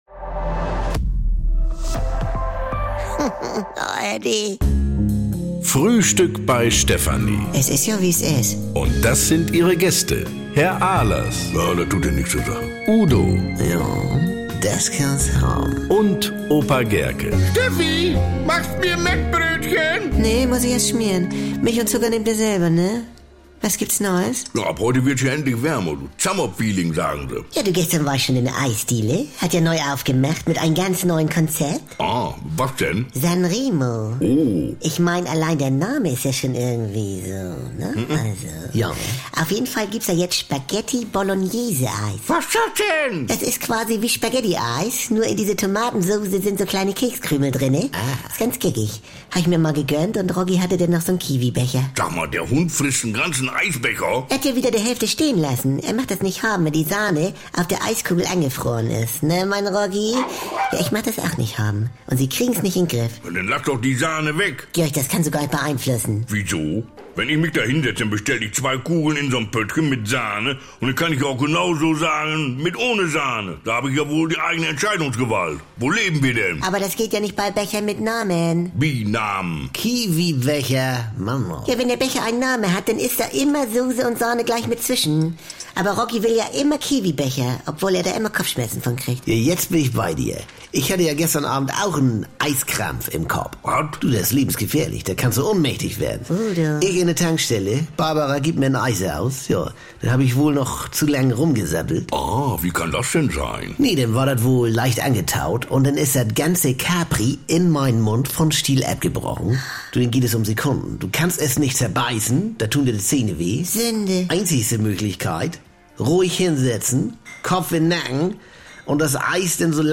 Die besten Folgen der Kult-Comedy gibt es im Radio bei den NDR Landesprogrammen in Niedersachsen, Mecklenburg-Vorpommern, Schleswig-Holstein und Hamburg.